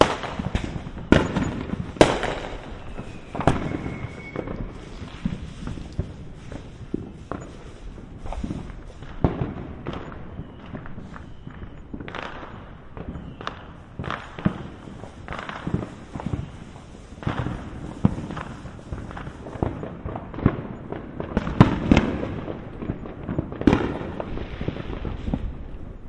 Fireworks single distant explosion
描述：Single firework explosion going off in various places within Santa Ana at a distance recorded with Roland CS10EM Binaural Microphones/Earphones and a Zoom H4n Pro. No Postprocessing added.
标签： binaural loud fieldrecord rockets firework fireworks explosions fieldrecording bang fourthofjuly firecrackers fieldrecording explosion fireworks firecrackers newyear boom exploding newyears binauralrecording explosive kaboom ambient rocket bomb binaur
声道立体声